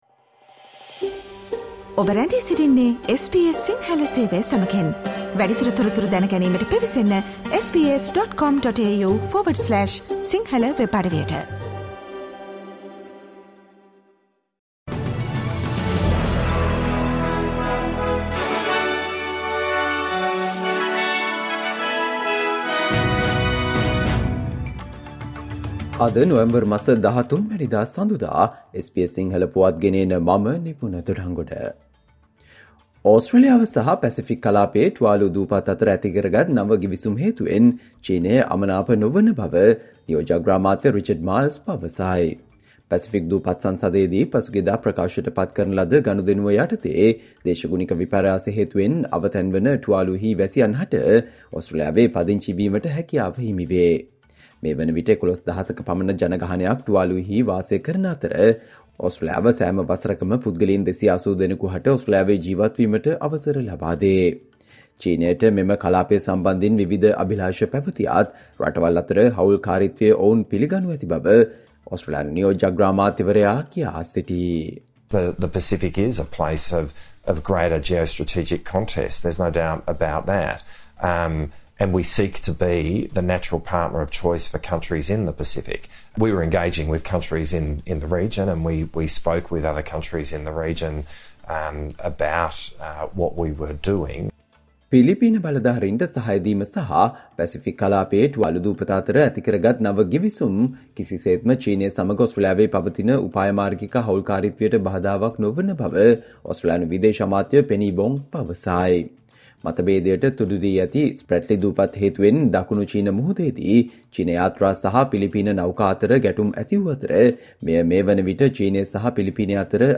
Australia news in Sinhala, foreign and sports news in brief - listen Sinhala Radio News Flash on Monday 13 November 2023.